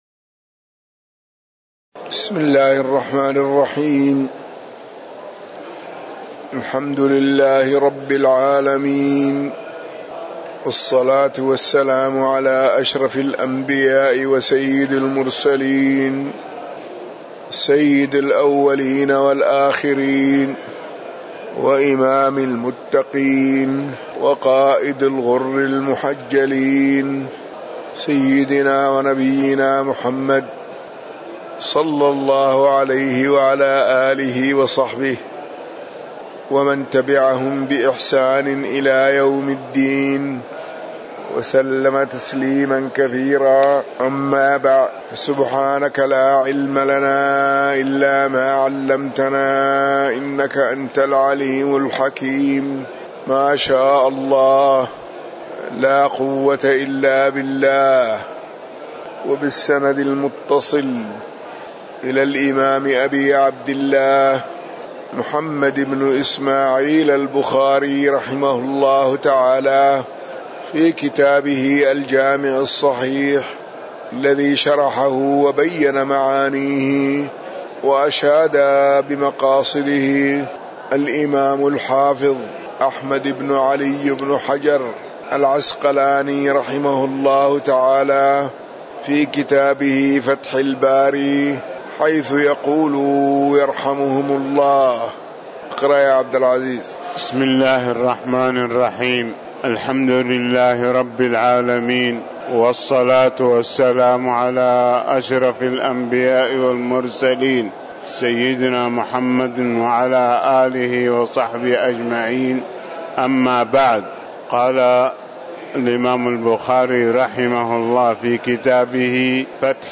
تاريخ النشر ٣ صفر ١٤٤١ هـ المكان: المسجد النبوي الشيخ